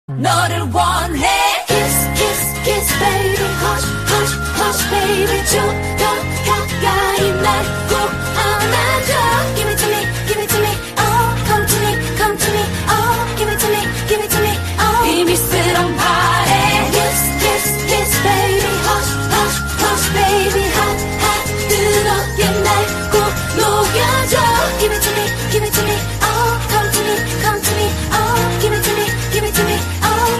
M4R铃声, MP3铃声, 日韩歌曲 121 首发日期：2018-05-15 22:07 星期二